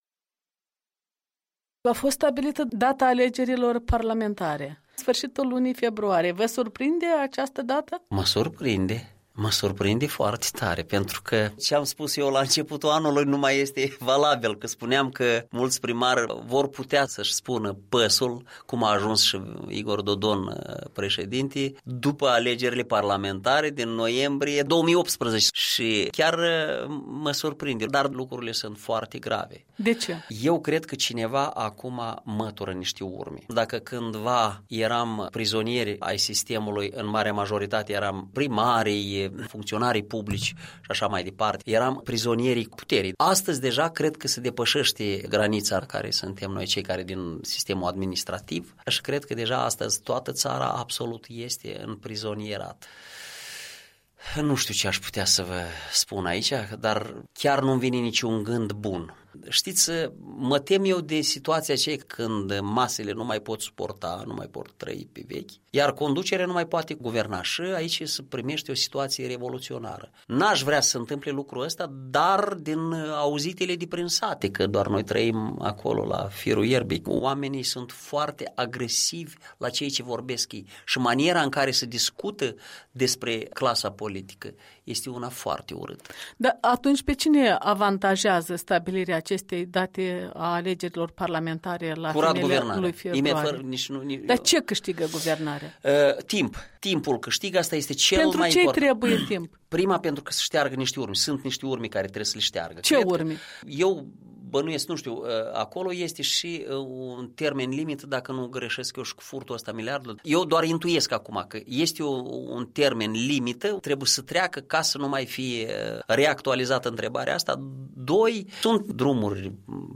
Interviu cu primarul de la Capaclia, raionul Cantemir, despre amînarea alegerilor parlamentare.